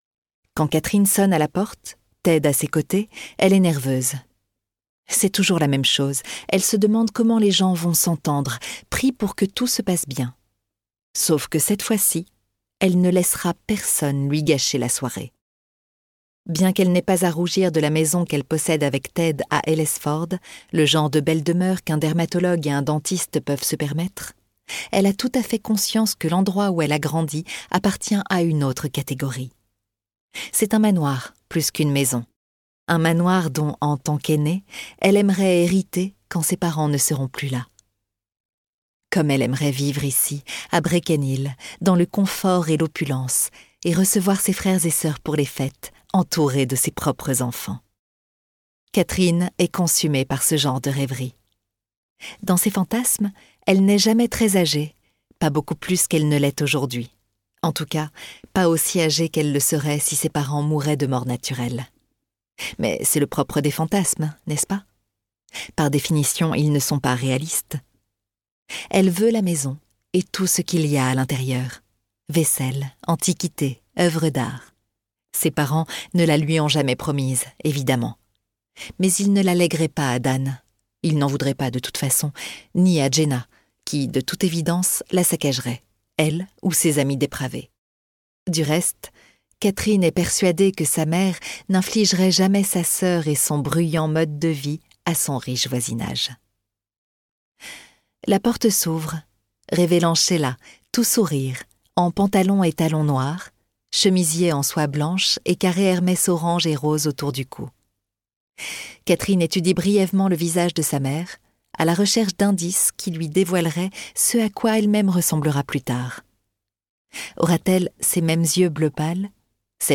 Thriller psychologique par Shari Lapena
La lecture précise et pince-sans-rire